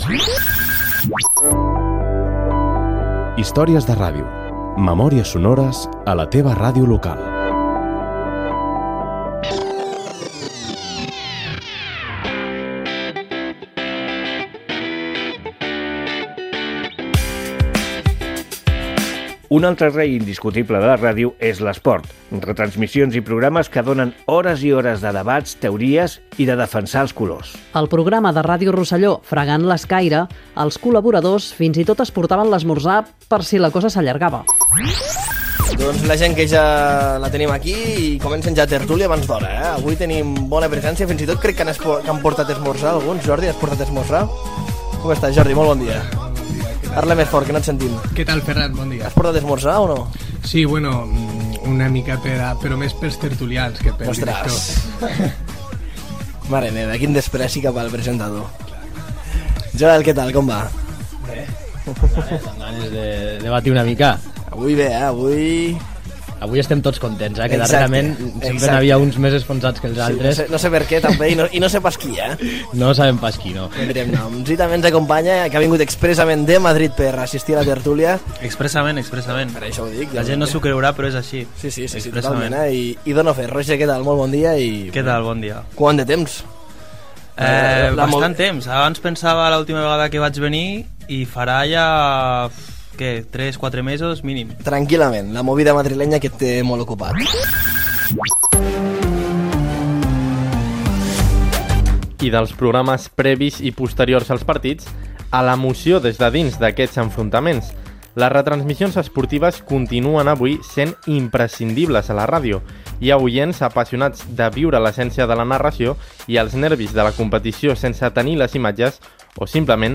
Fragment del programa "Fregant l'escaire" de Ràdio Rosselló i de transmissions esportives de Ràdio Ciutat de Badalona i Ràdio Calella Televisió i de la Diada castellera a Ràdio Vilafranca.
Entreteniment